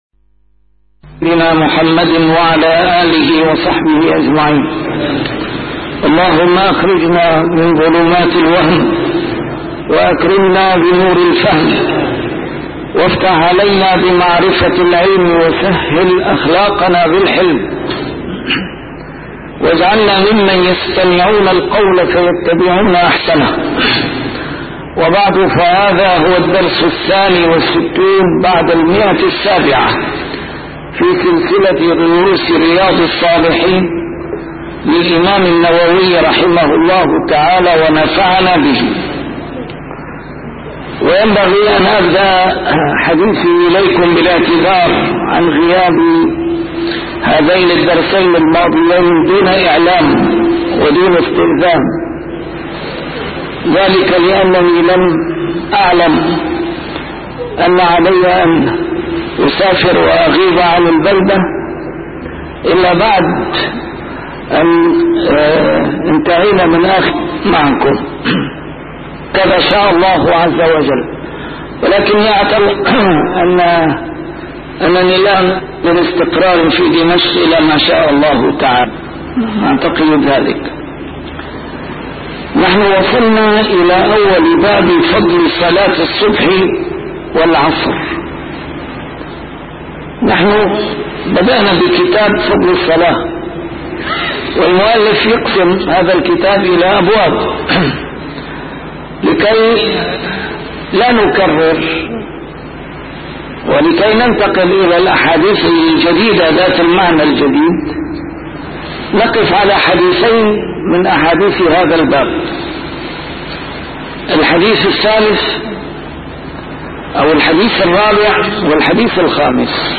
A MARTYR SCHOLAR: IMAM MUHAMMAD SAEED RAMADAN AL-BOUTI - الدروس العلمية - شرح كتاب رياض الصالحين - 762- شرح رياض الصالحين: فضل صلاة الصبح والعصر